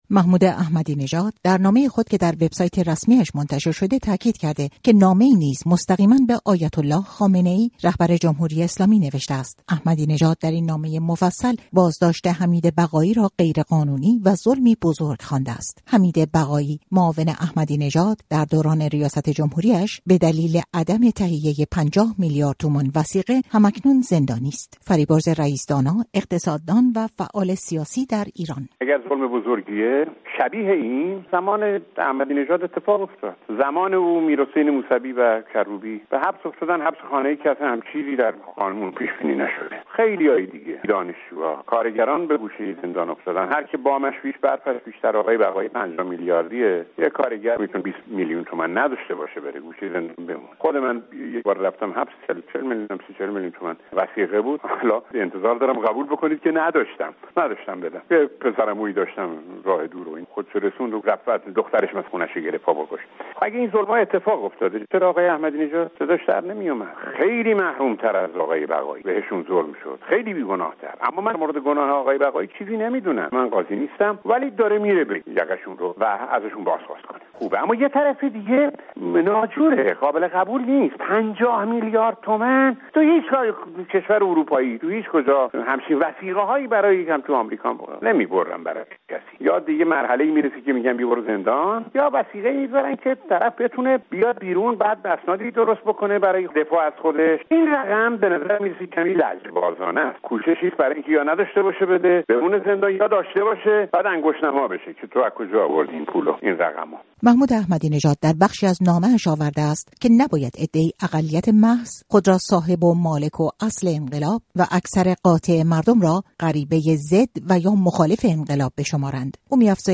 گزارش رادیویی در مورد درخواست محمود احمدی‌نژاد برای آزادی حمید بقایی